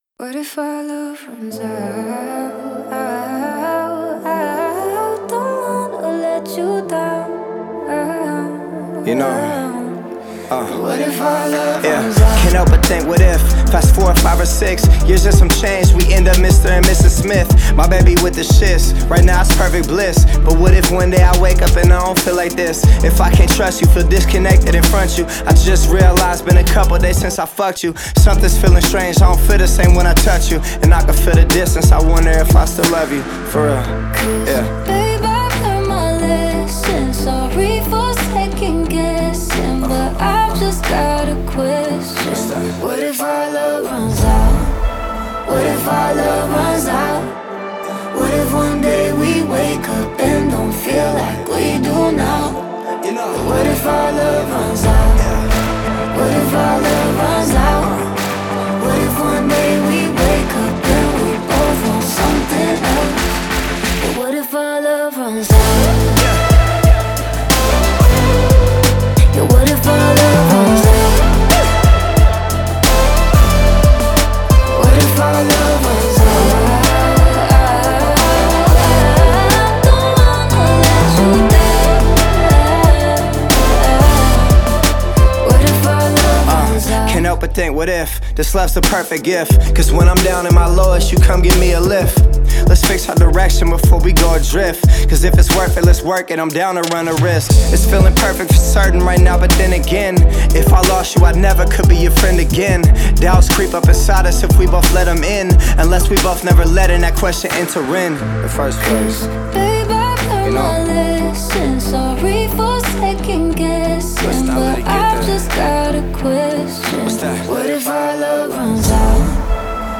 это динамичная композиция в жанре EDM с элементами хип-хопа